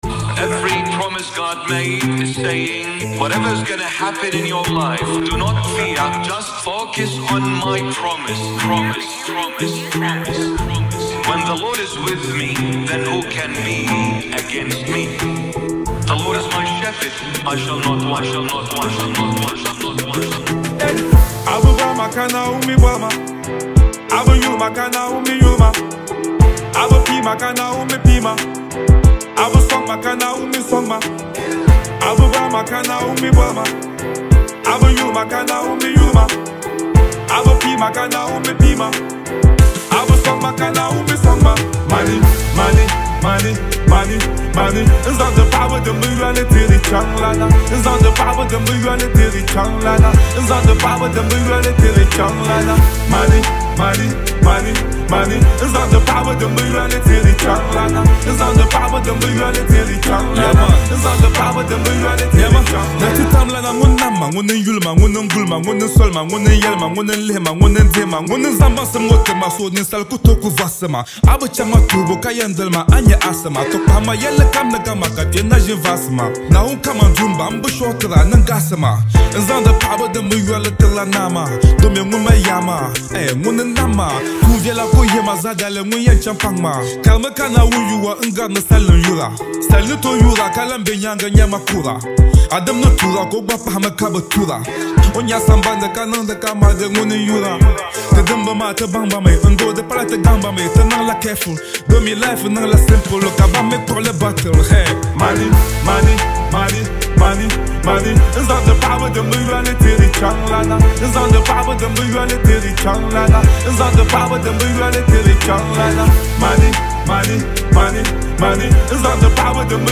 ” a bold, street-certified banger released on June 28
hard-hitting bars in Dagbani and English